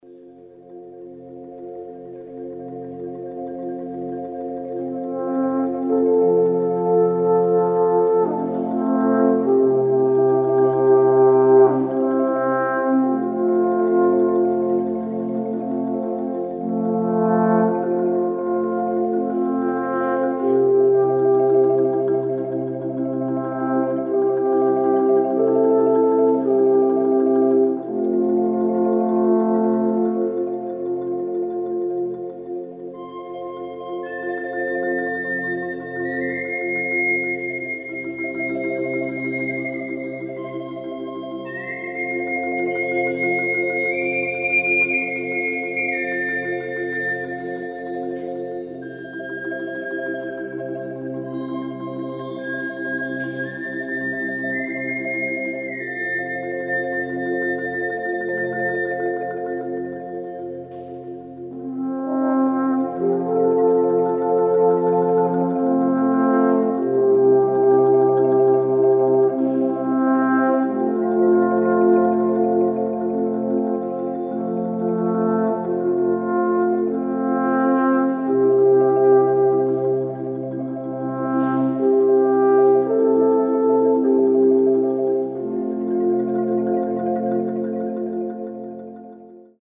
Alphorn
Windspiel
Marimba
Windflöte